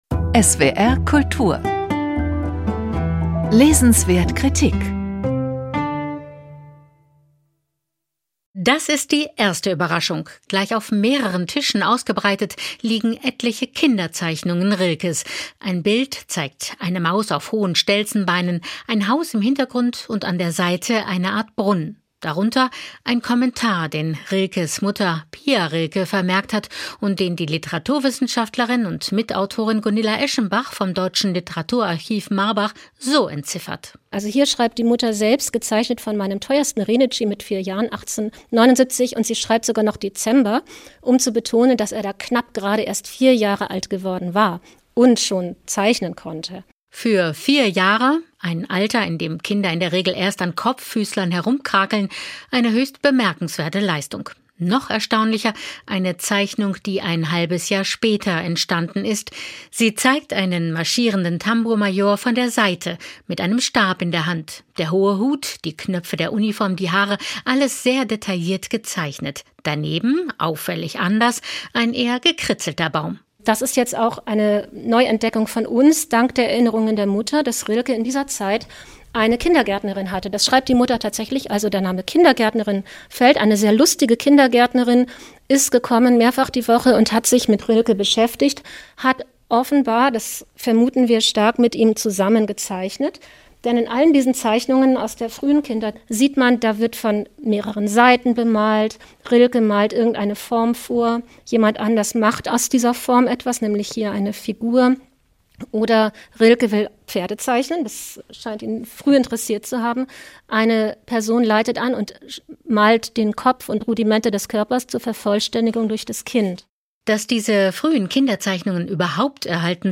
Bericht von